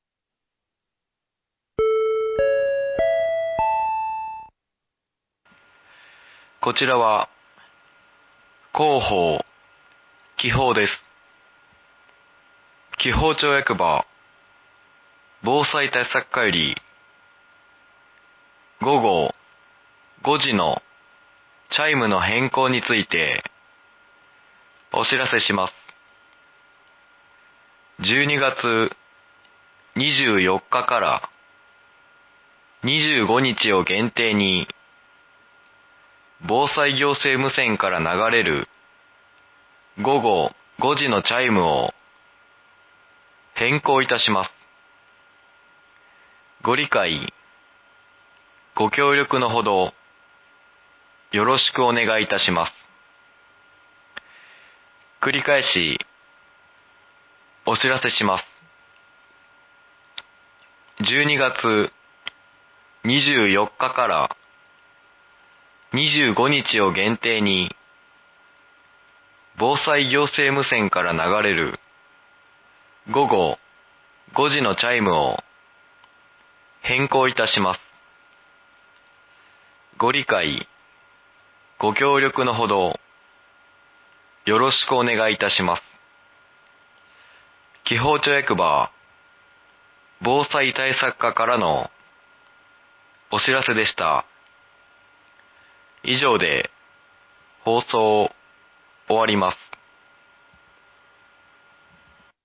12月24日から25日を限定に、防災行政無線から流れる午後５時のチャイムを変更いたします。